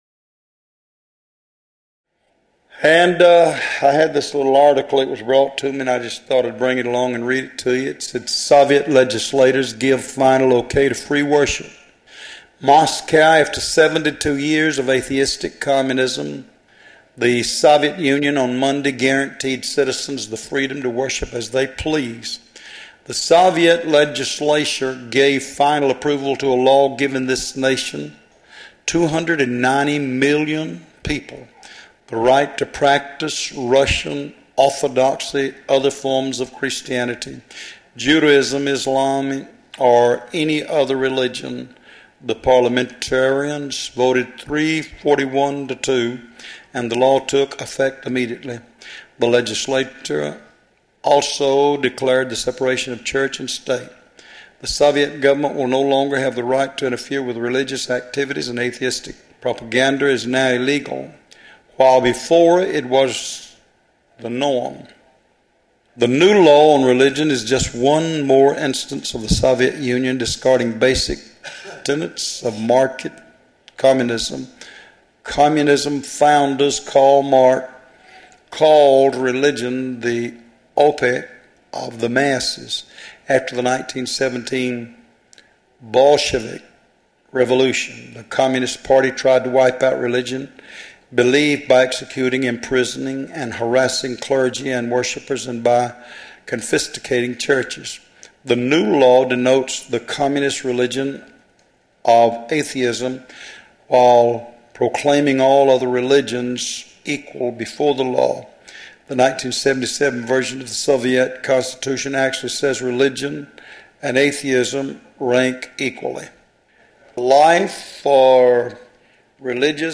Location: Love’s Temple in Monroe, GA USA
Sermons